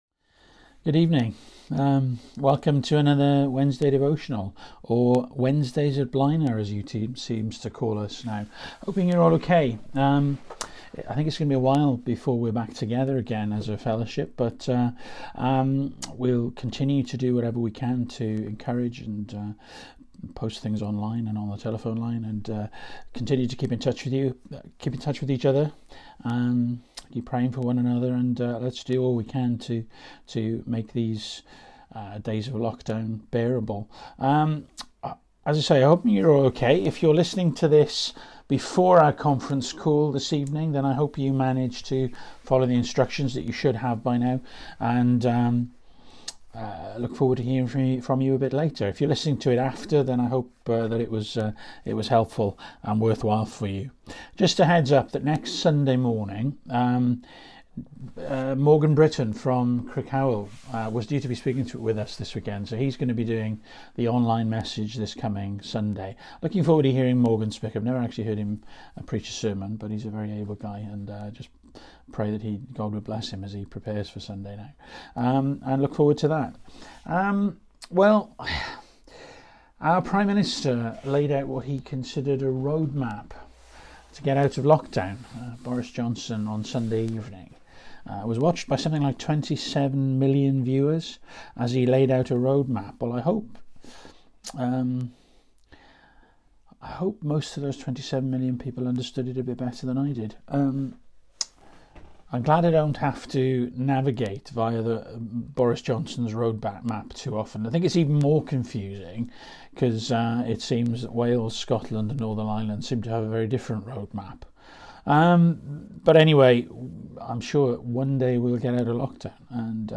Series: Wednesday Devotional